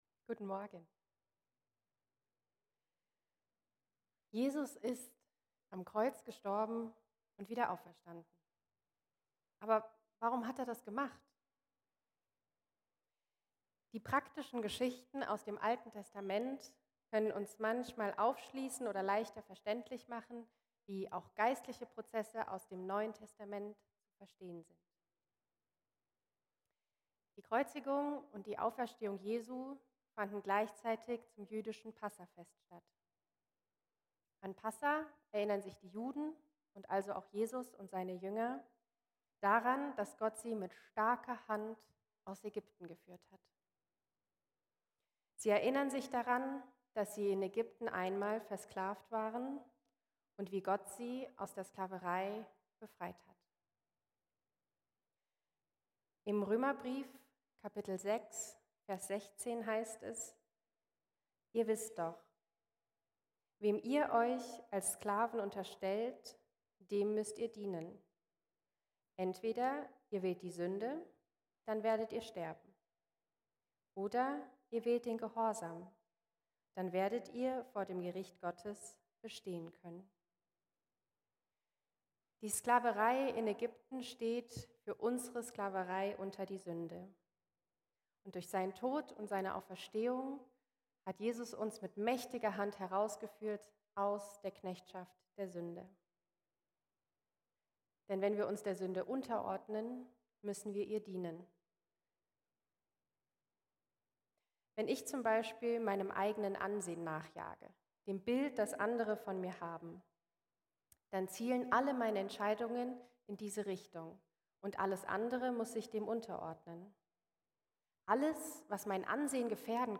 Frühmorgens feiern wir gemeinsam, dass Jesus von den Toten